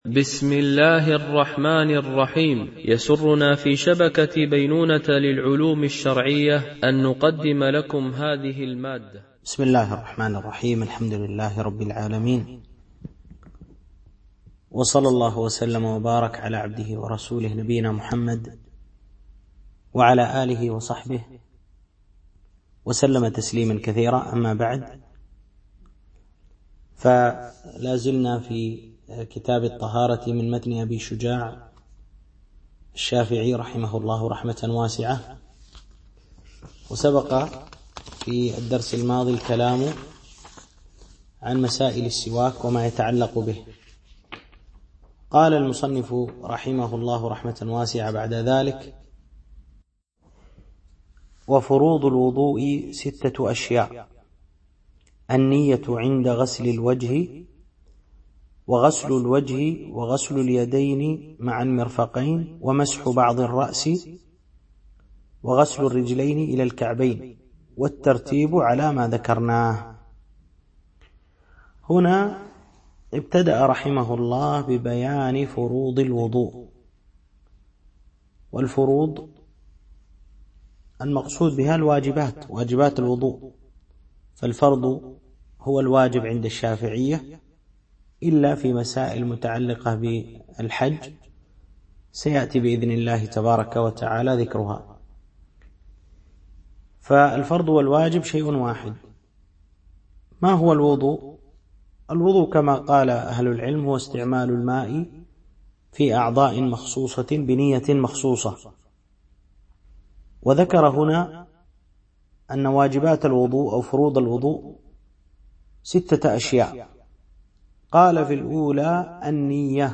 شرح متن أبي شجاع في الفقه الشافعي ـ الدرس 3